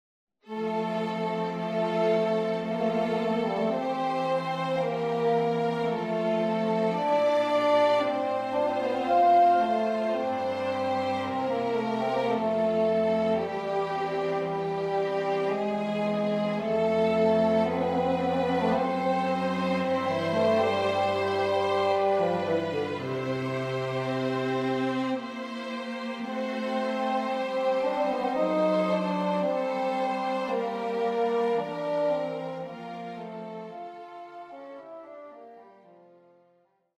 wrote a virtuoso adagio for his first wife
solo bassoon, accompanied by violin, viola and cello